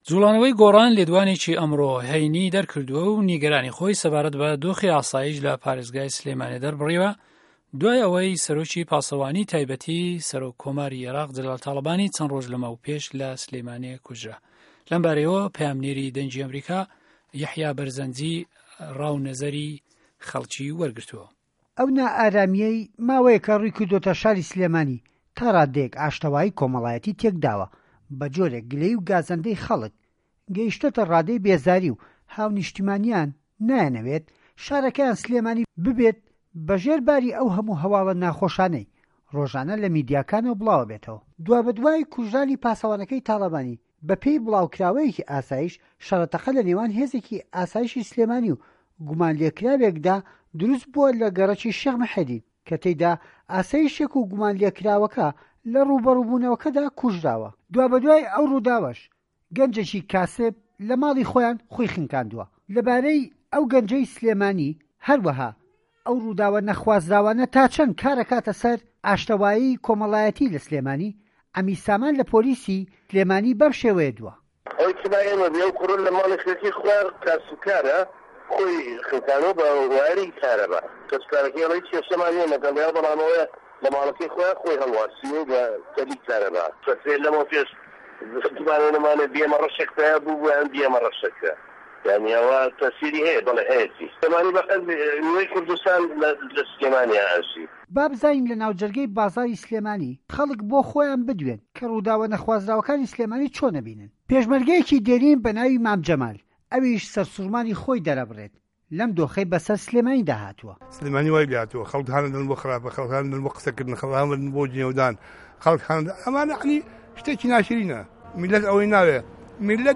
ده‌قی ڕاپـۆرت و وتووێژه‌که‌